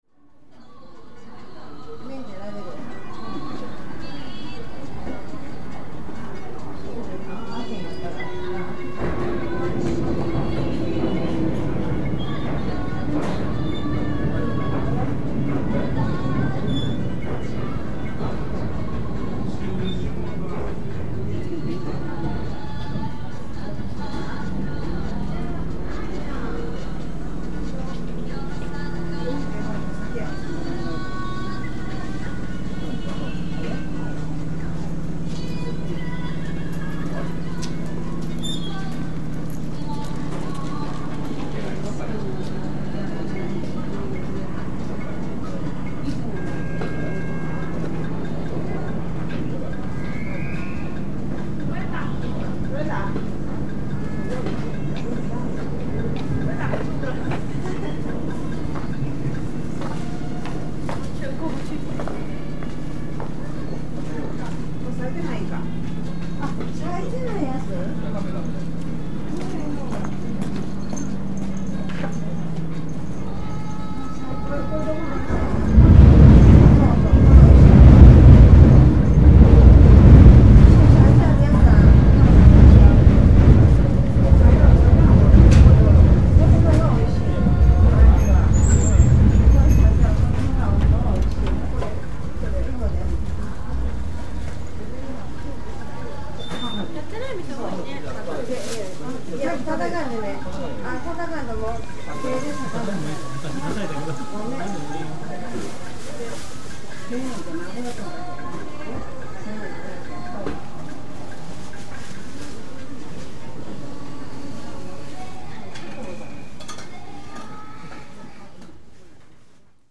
近鉄の鶴橋駅沿いに東のほうへ歩くと市場街になります。
天井からは電車の音。複々線だから時々同時に２本の電車が走っていきます。